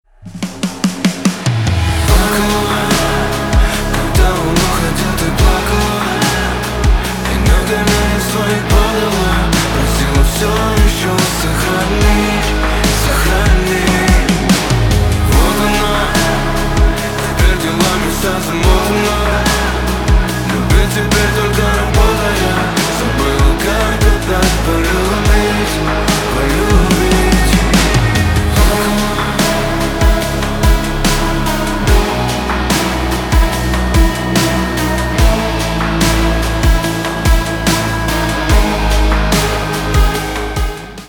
Рок рингтоны
Рок ремикс на звонок